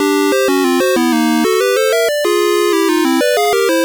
Source Recorded from the Sharp X1 version.